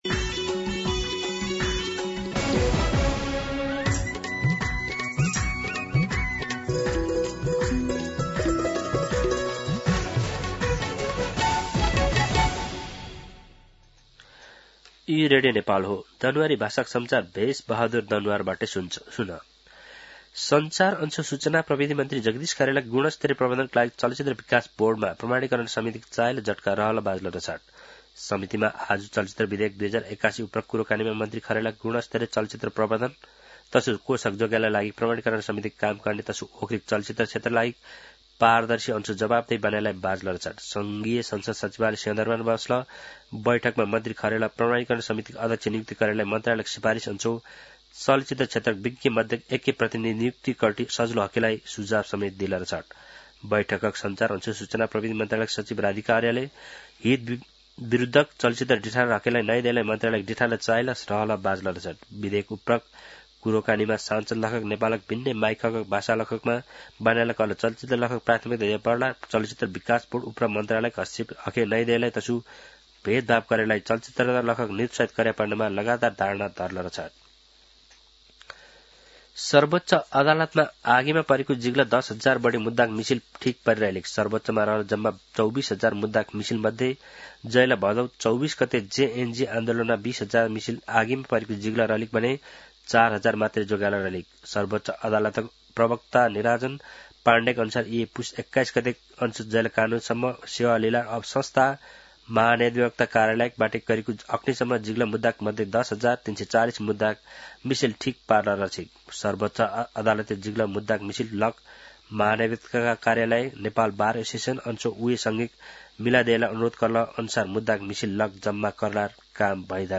दनुवार भाषामा समाचार : २२ पुष , २०८२
Danuwar-News-09-22.mp3